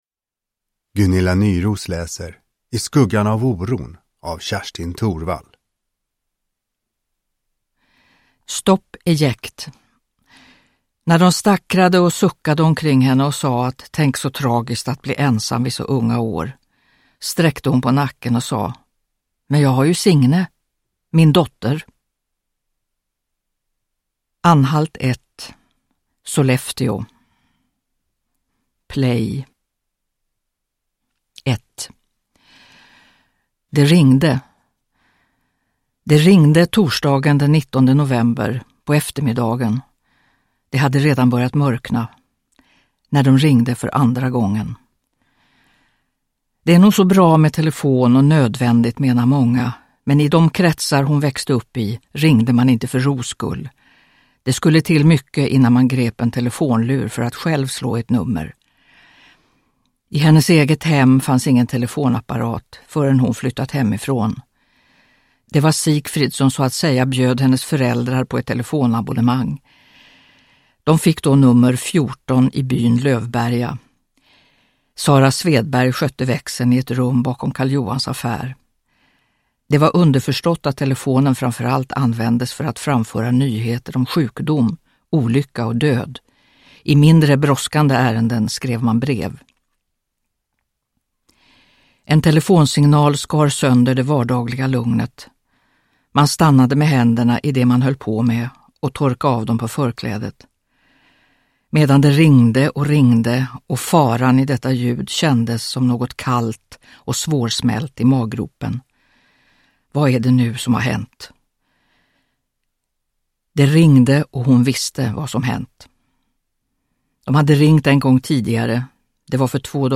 Uppläsare: Gunilla Nyroos
Ljudbok